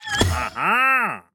Minecraft Version Minecraft Version latest Latest Release | Latest Snapshot latest / assets / minecraft / sounds / mob / wandering_trader / reappeared1.ogg Compare With Compare With Latest Release | Latest Snapshot